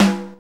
RINGER.wav